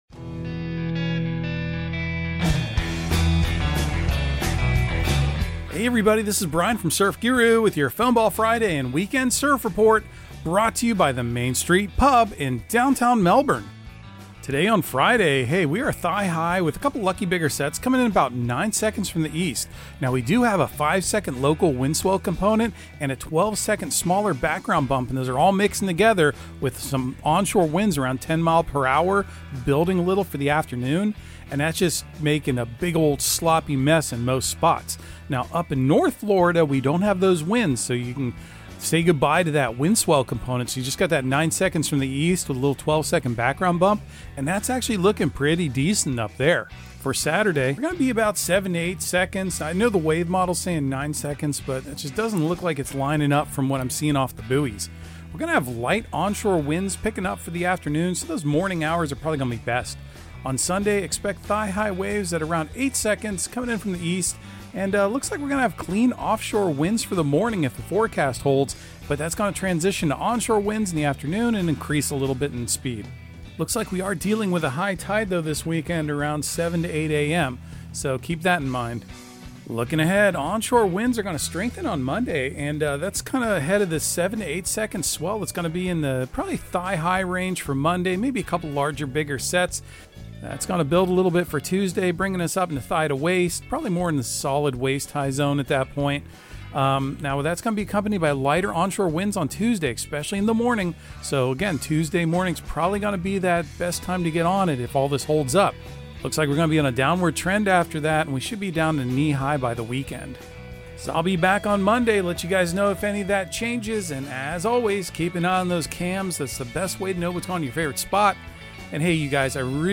Audio surf report and surf forecast on April 25 for Central Florida and the Southeast. Your host will also enlighten you on current events in the surfing industry and talk about events and entertainment happenings in the local and regional area.